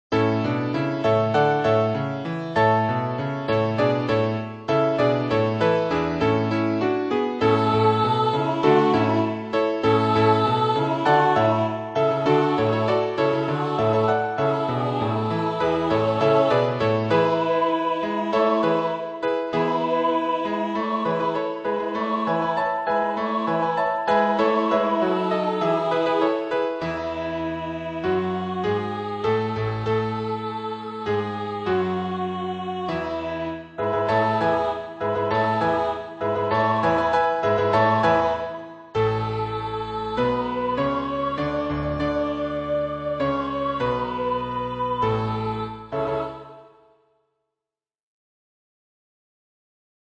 Two-part vocal.